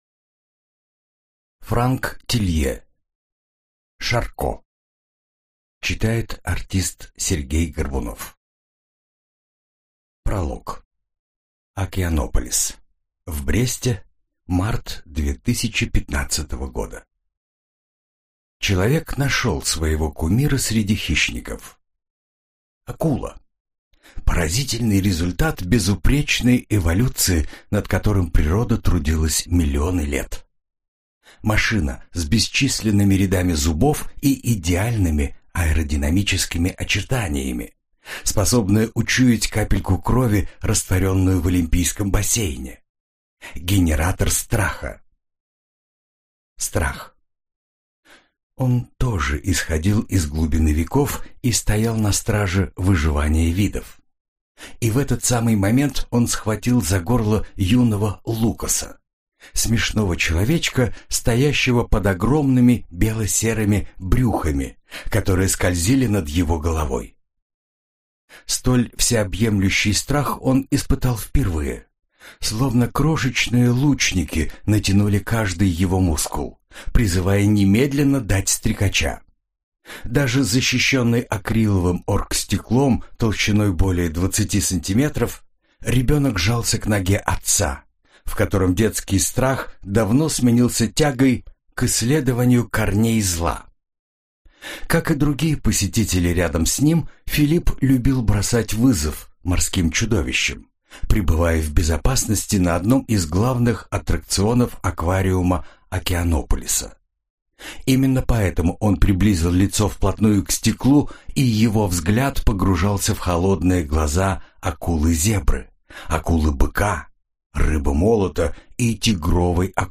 Аудиокнига Шарко - купить, скачать и слушать онлайн | КнигоПоиск
Аудиокнига «Шарко» в интернет-магазине КнигоПоиск ✅ в аудиоформате ✅ Скачать Шарко в mp3 или слушать онлайн